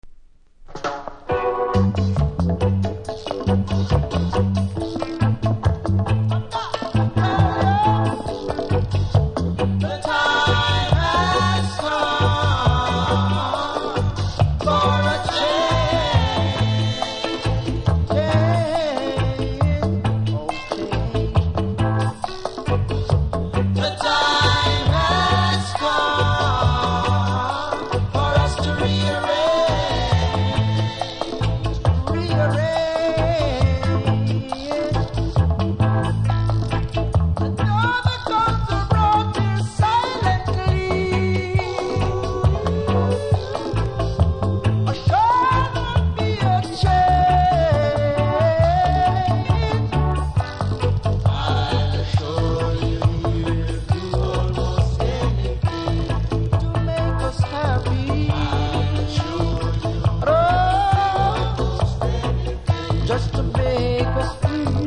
曲の後半はVERSIONでショーケース・スタイルです。
多少うすキズはありますが音には影響せず良好です。